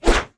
binf_swing2.wav